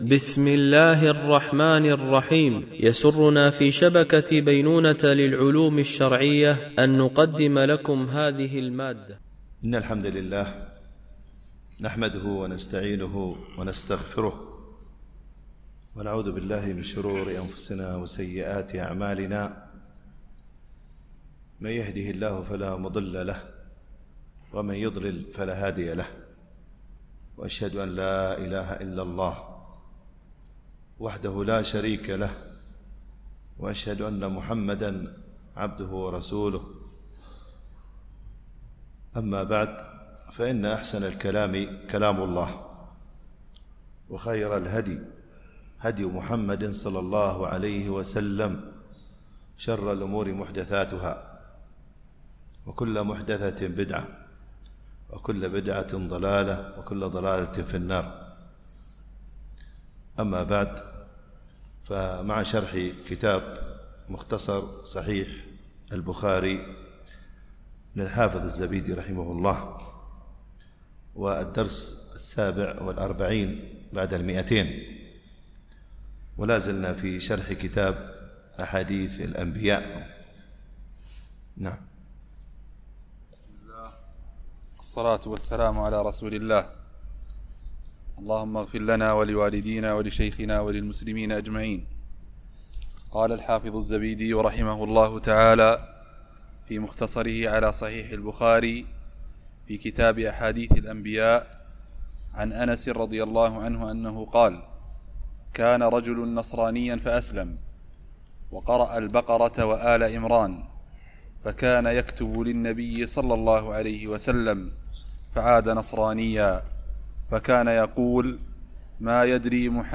شرح مختصر صحيح البخاري ـ الدرس 247 ( كتاب أحاديث الأنبياء - الجزء السابع والعشرون - الحديث 1512 - 1516 )